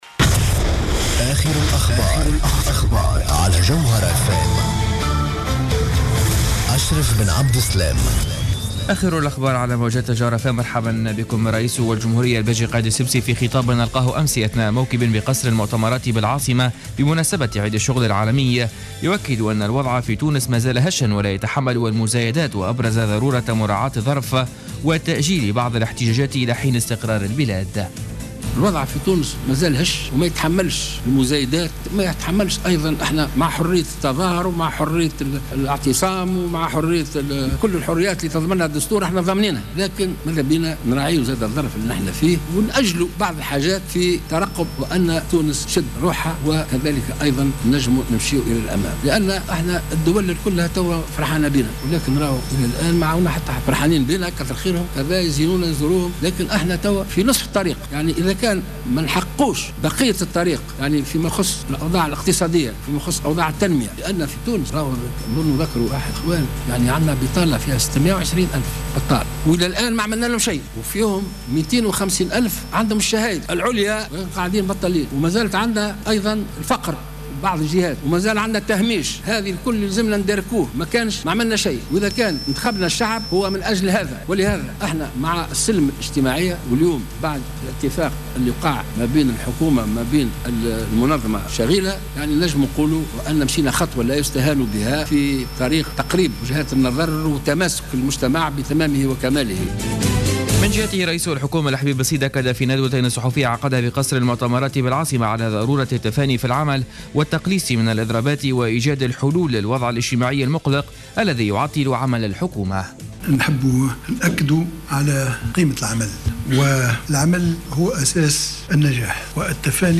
نشرة أخبار منتصف الليل ليوم السبت 02 ماي 2015